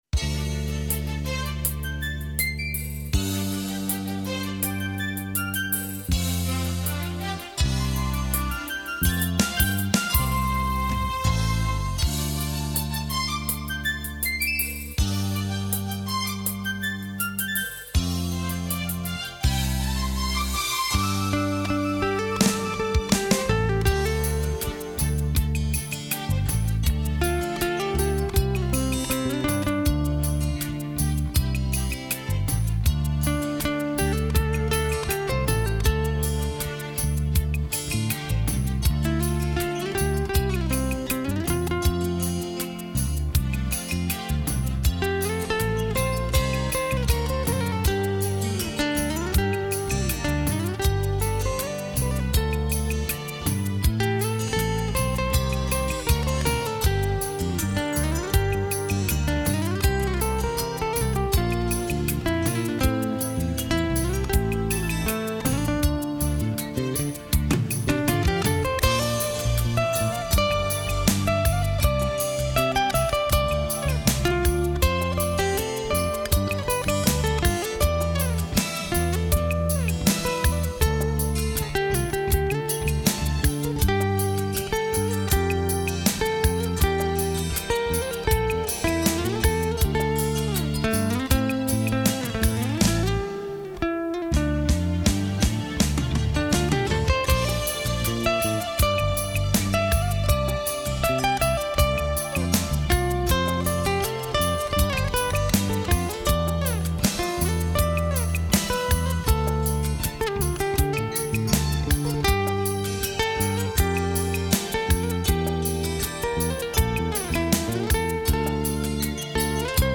* Ca sĩ: Không lời
* Thể loại: Việt Nam